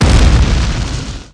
1 channel
explosion01.mp3